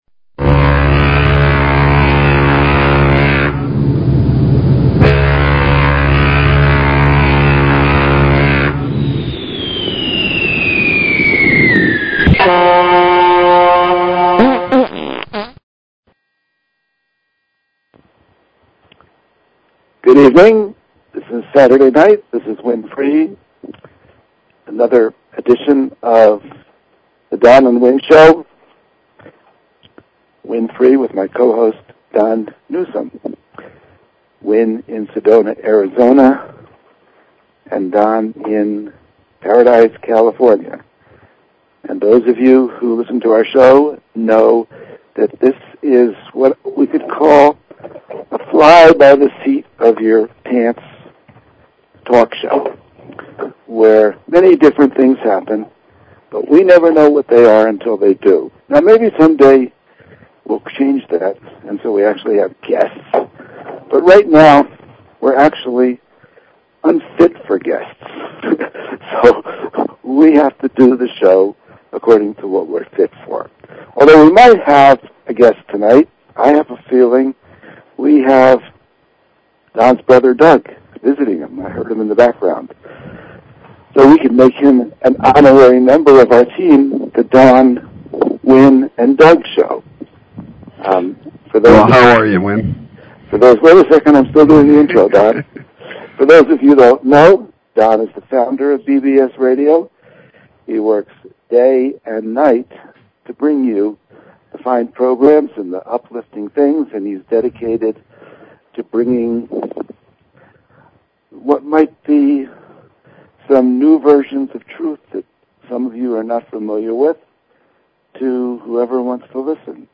Talk Show Episode
Drive time radio with a metaphysical slant.